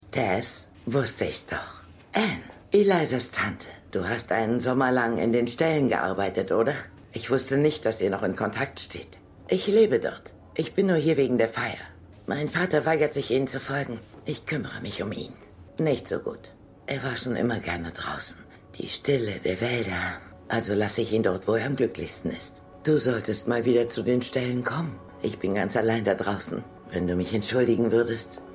Ihre Stimme ist seit einiger Zeit so rauchig.